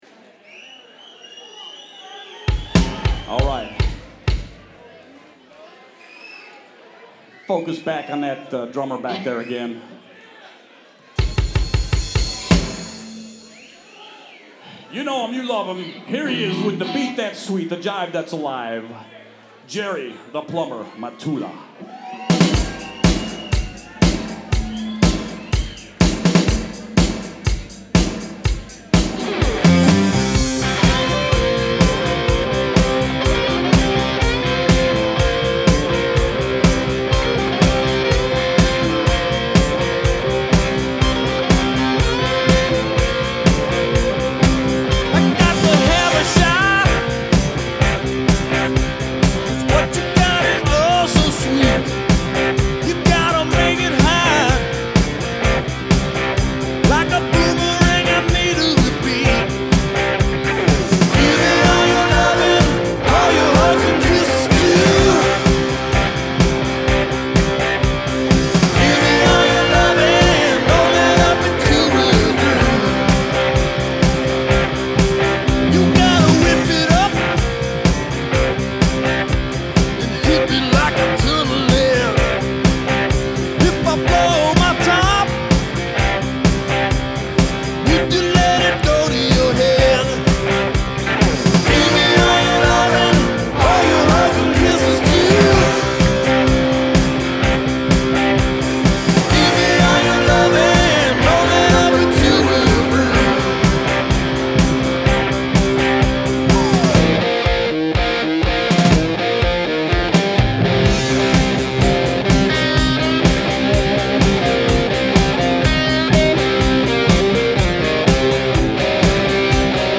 Spinning Fuzzy Guitars?